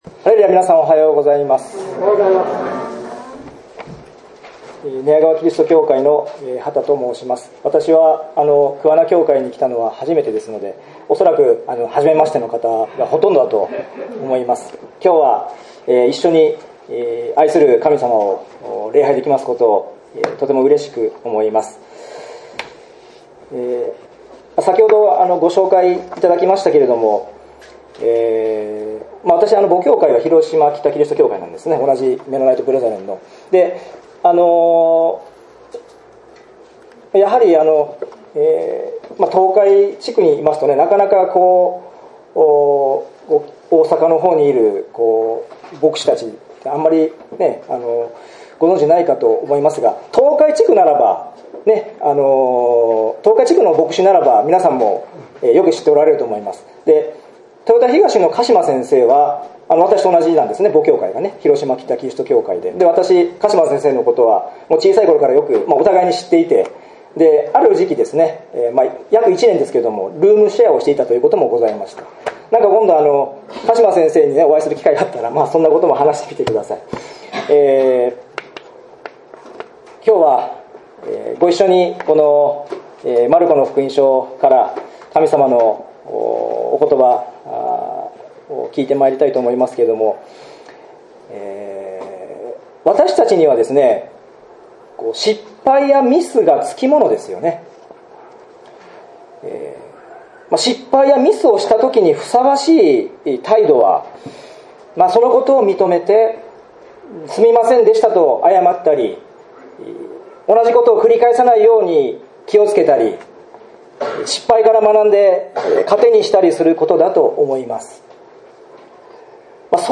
メッセージ